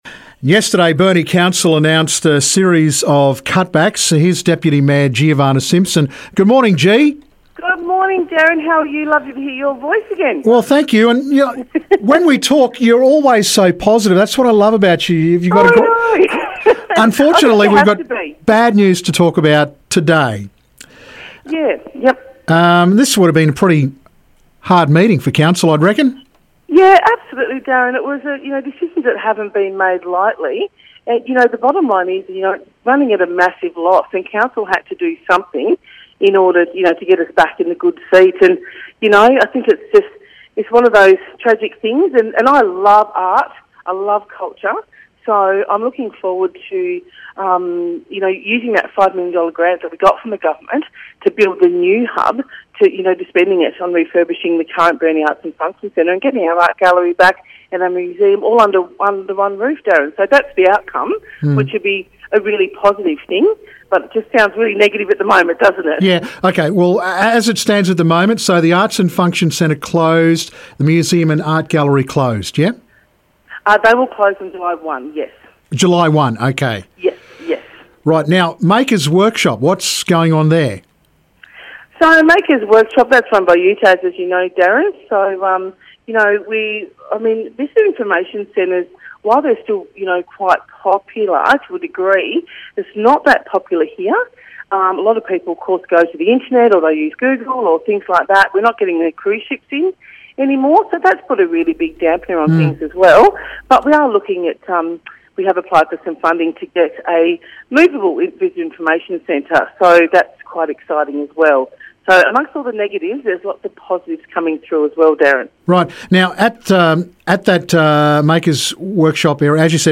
Burnie Deputy Mayor Giovanna Simpson talks about cut backs.